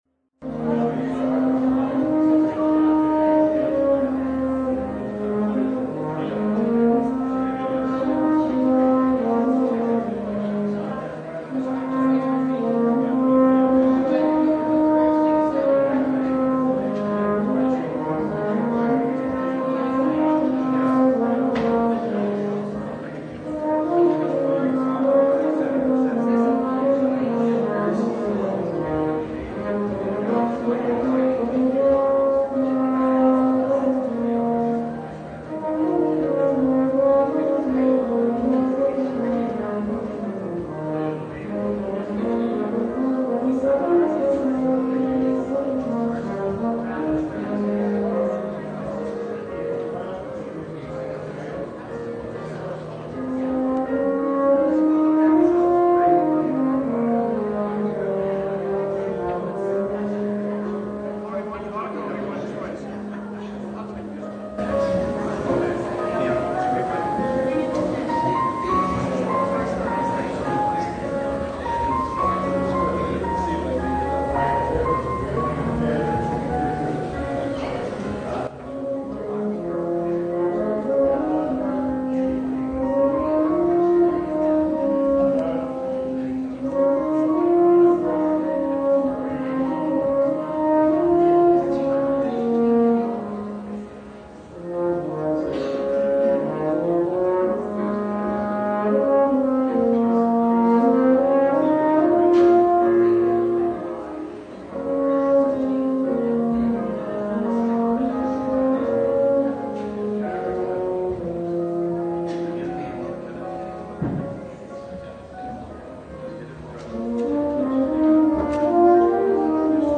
Service Type: Christmas Day
(John 1:14) Download Files Bulletin Topics: Full Service « What Child Is This?